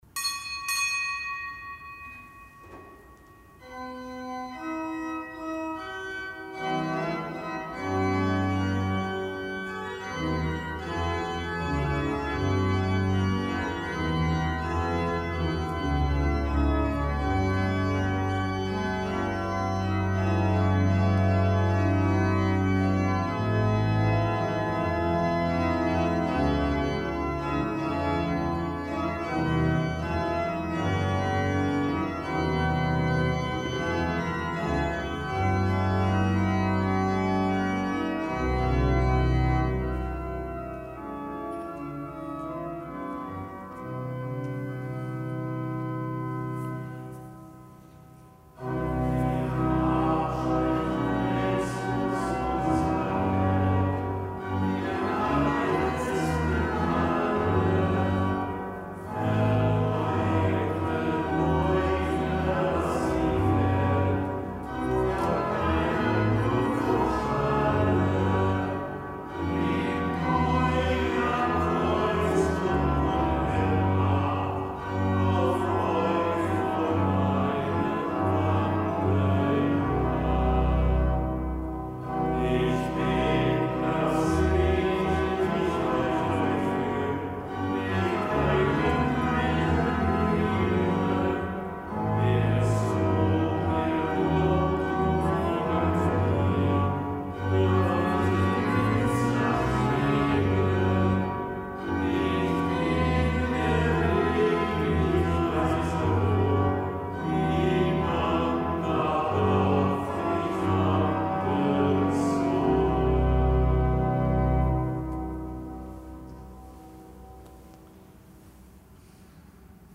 Kapitelsmesse aus dem Kölner Dom am Montag der sechsten Woche im Jahreskreis, nichtgebotener Gedenktag der Heiligen Sieben Gründer des Servitenordens. Zelebrant: Weihbischof Rolf Steinhäuser.